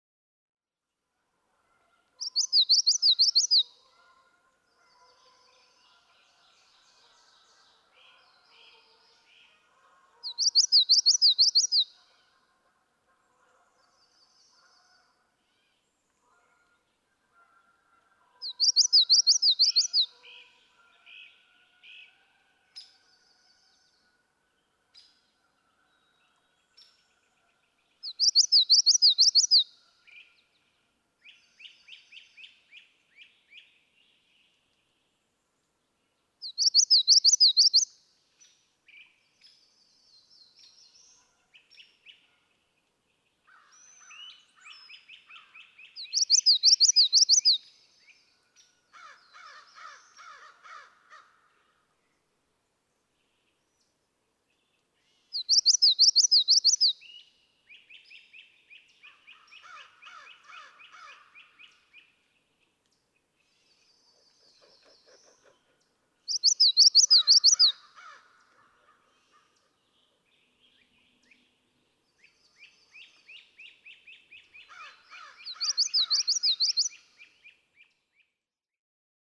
Common yellowthroat
♫24. Wichity-wichity-wichity song. May 17, 2012. Satan's Kingdom Wildlife Management Area, Northfield, Massachusetts. (1:19)
024_Common_Yellowthroat.mp3